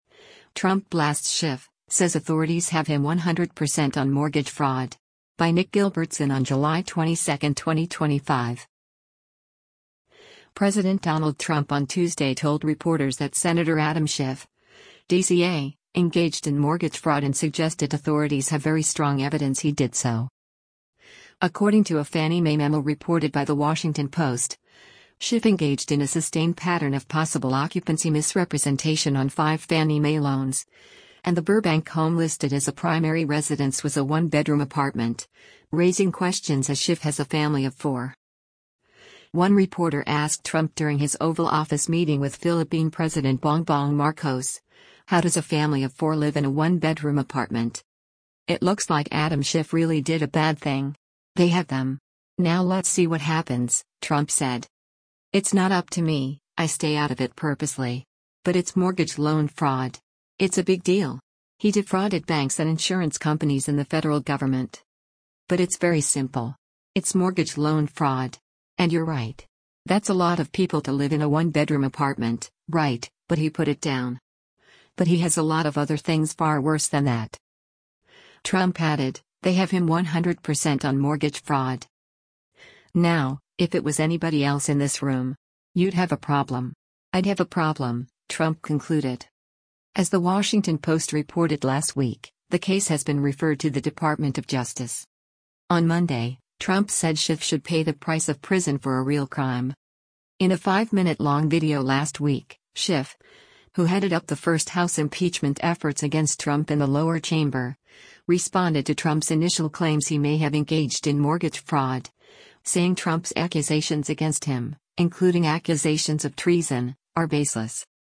President Donald Trump on Tuesday told reporters that Sen. Adam Schiff (D-CA) engaged in mortgage fraud and suggested authorities have very strong evidence he did so.
One reporter asked Trump during his Oval Office meeting with Philippine President Bongbong Marcos, “How does a family of four live in a one-bedroom apartment?”